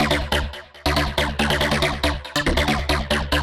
Index of /musicradar/future-rave-samples/140bpm
FR_Boingo_140-D.wav